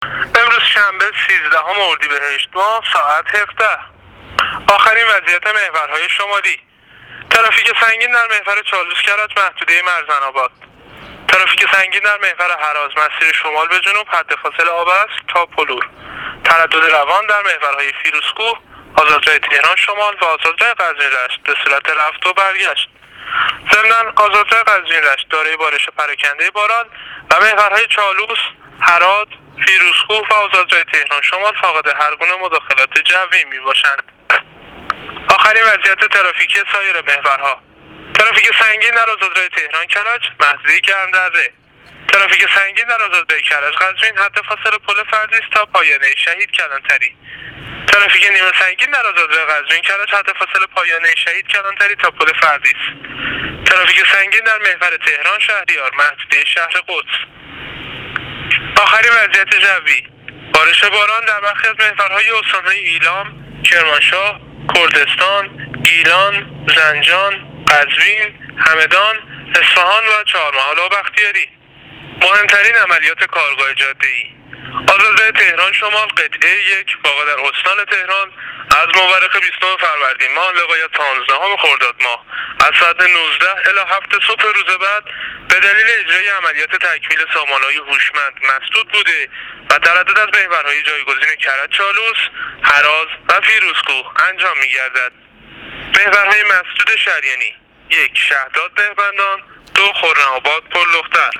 گزارش رادیو اینترنتی از آخرین وضعیت ترافیکی جاده‌ها تا ساعت ۱۷، سیزدهم اردیبهشت ۱۳۹۹